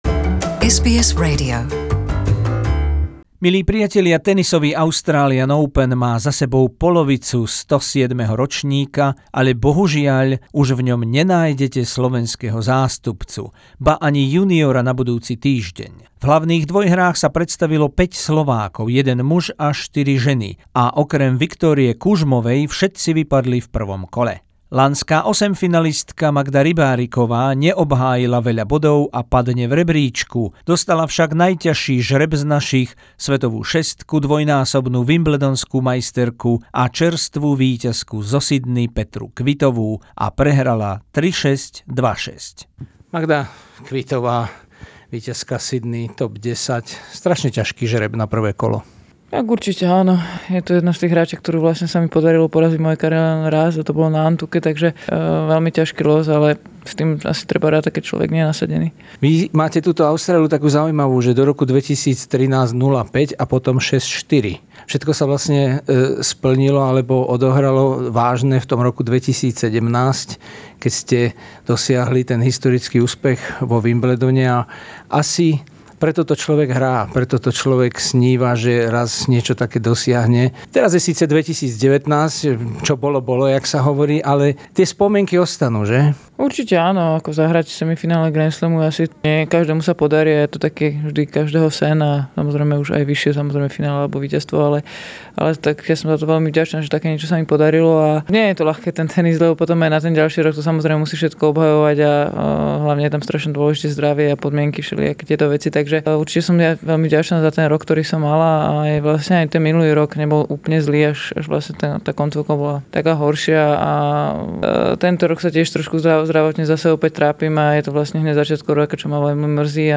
Reportáž a rozhovory z tenisových majstrovstiev Austrálie v Melbourne 2019